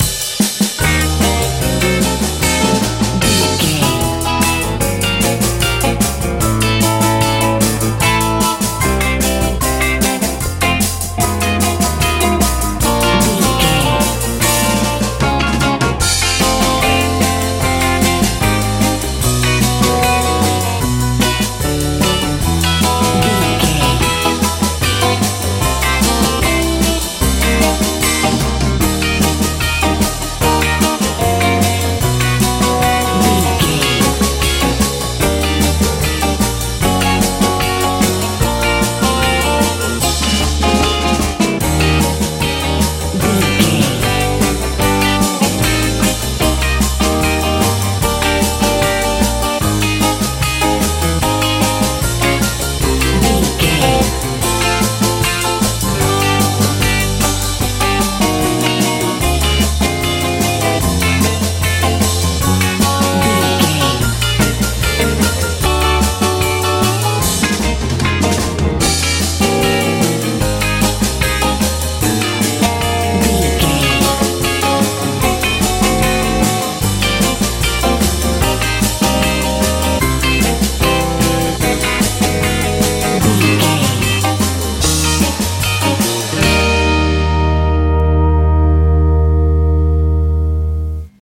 surf pop
Ionian/Major
E♭
piano
acoustic guitar
bass guitar
drums
smooth
positive
optimistic
lively
joyful